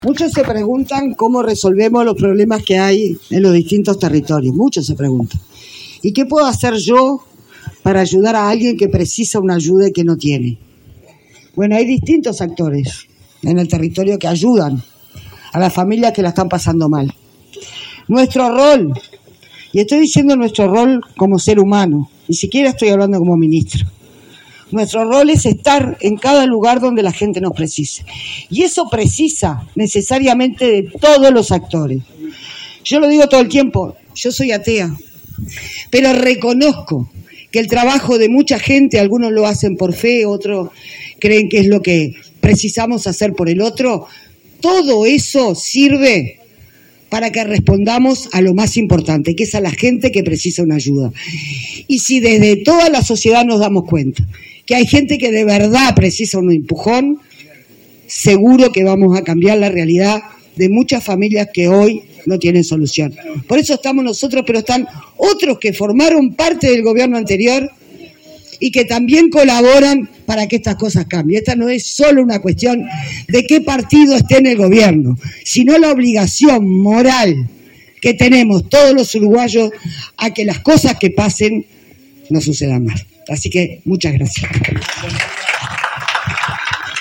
Palabras de la ministra de Vivienda y Ordenamiento Territorial, Cecilia Cairo
La ministra de Vivienda y Ordenamiento Territorial, Cecilia Cairo, se reunió, este 20 de marzo, con el equipo que integra el programa Rancho Cero, gestionado por la asociación civil Cireneos. En el encuentro se firmó un convenio entre la asociación Cirineos y la fundación Pérez Scremini, que permitió la entrega de la primera vivienda en el barrio La Colmena.